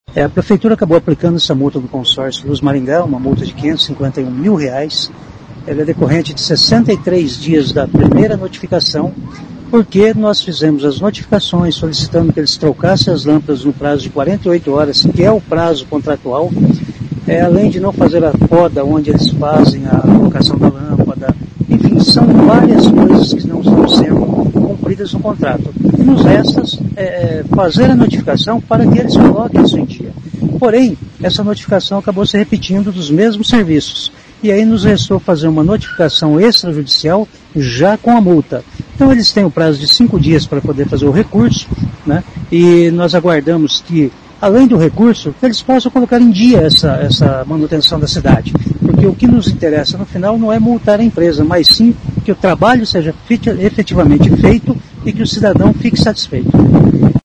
Ouça o que diz o secretário:.